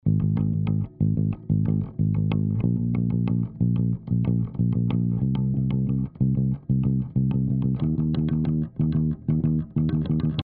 ベースの音をソロで、
• かかってない音